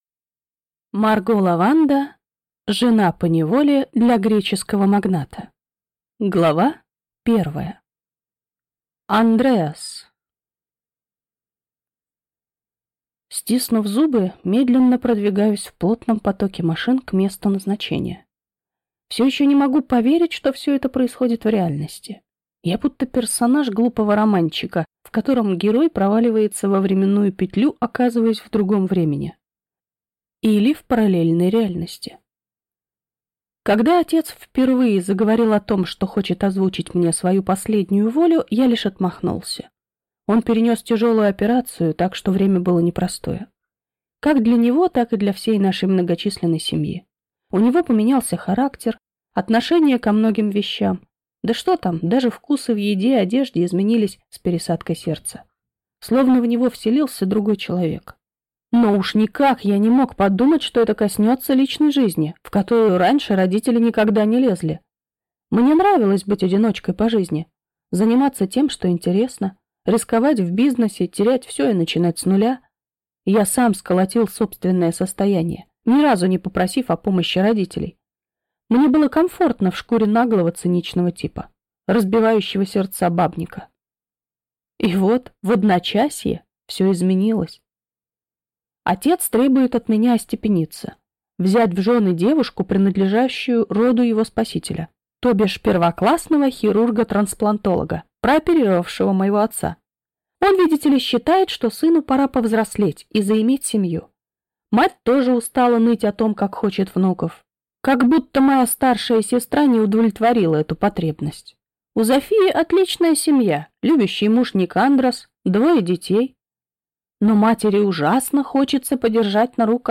Аудиокнига Жена поневоле для греческого магната | Библиотека аудиокниг